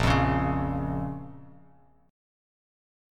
A#m Chord
Listen to A#m strummed